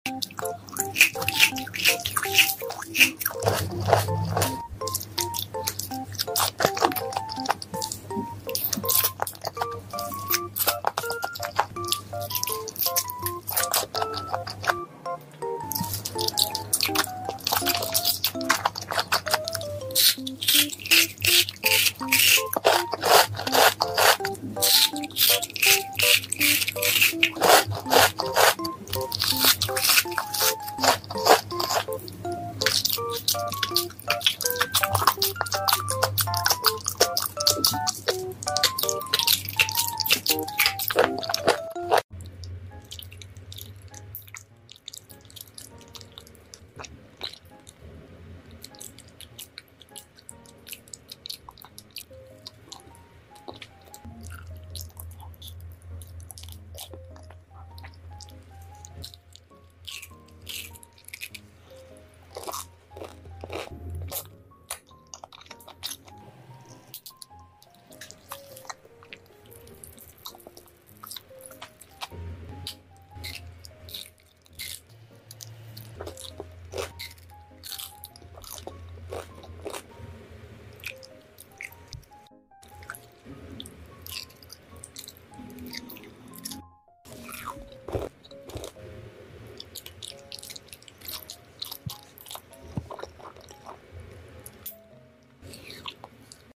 ASMR! 😋😋😋 Yummy . sound effects free download